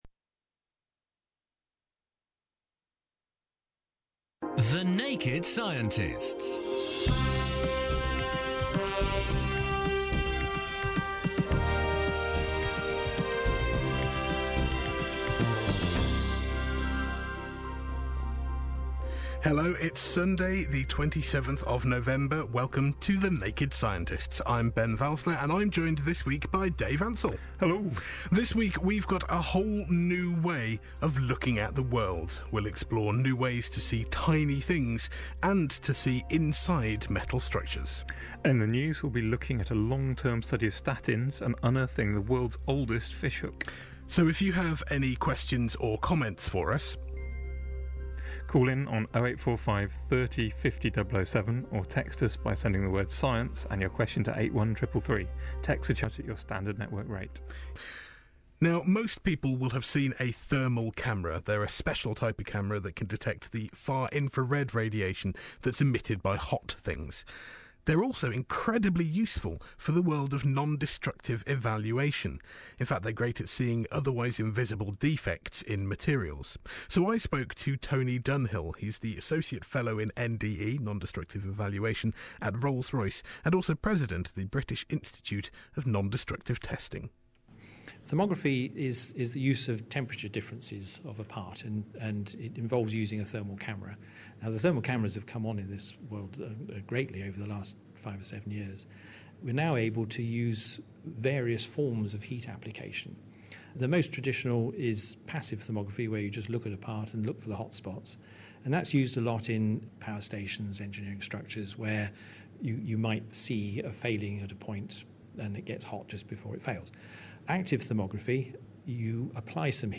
Extract from a radio programme involving interviews